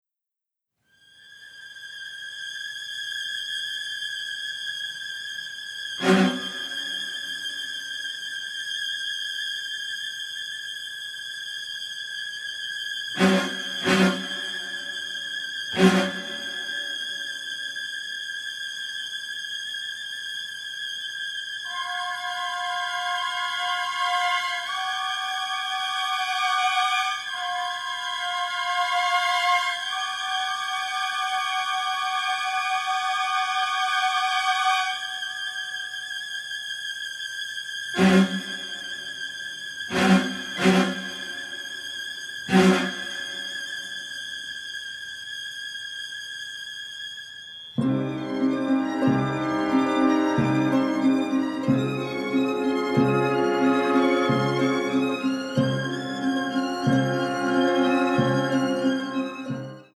dynamic score